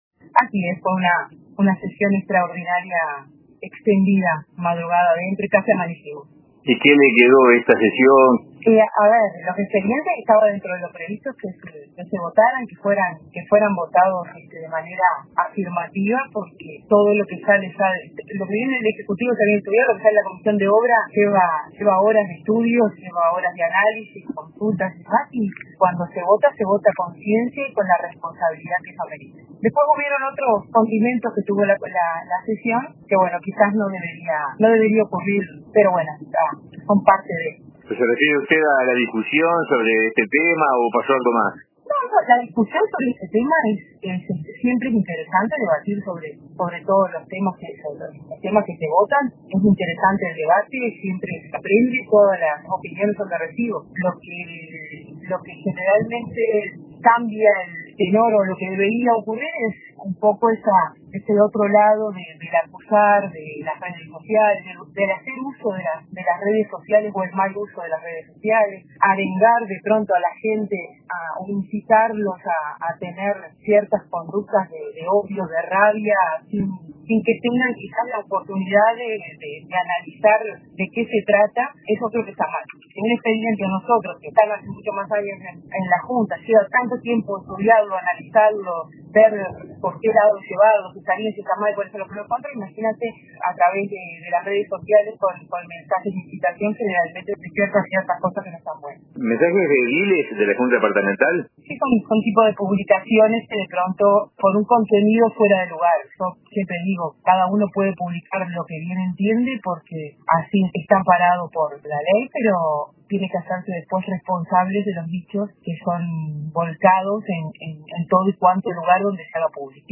La edil Verónica Robaina, integrante de la comisión de obras de la Junta Departamental por el Partido Nacional, dijo a RADIO RBC que una cosa es la opinión sobre los proyectos, pero algo muy doloroso es que se publiquen en las redes mensajes acusatorios, incitaciones y ofensas.